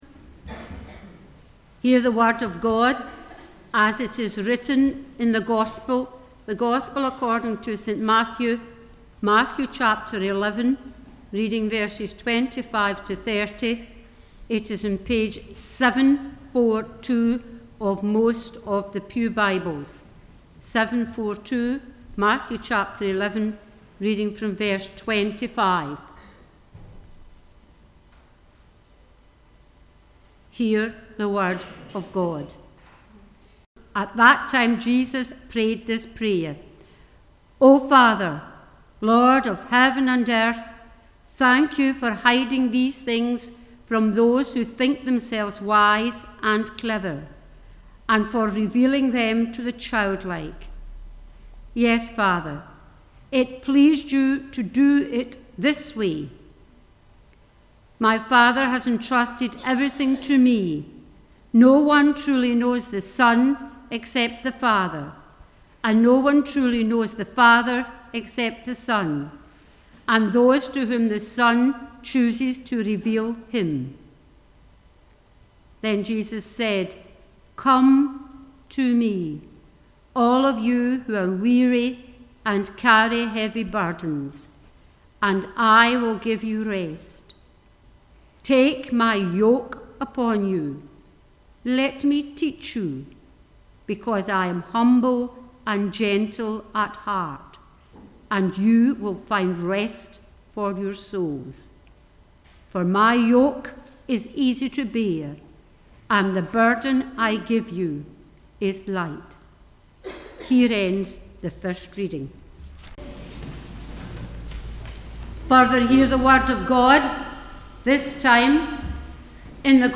The Scripture Readings prior to the Sermon are Matthew 11: 25-30 and Luke 10: 25-37